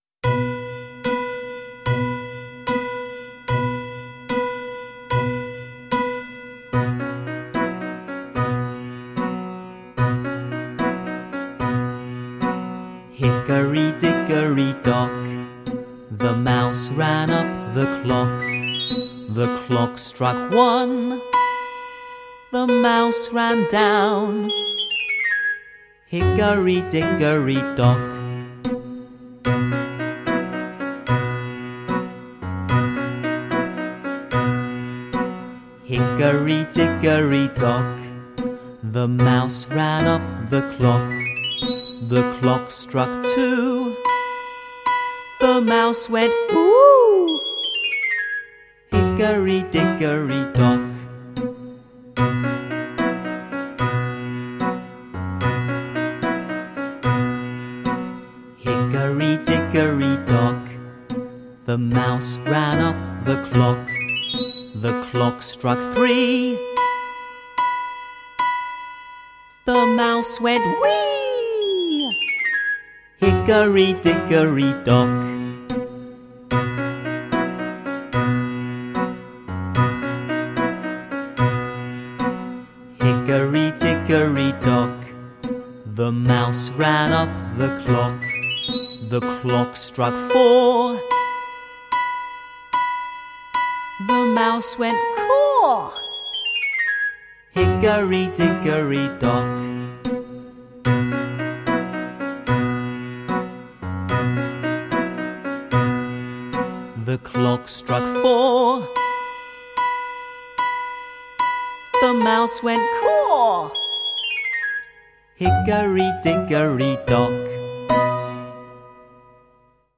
Песни-потешки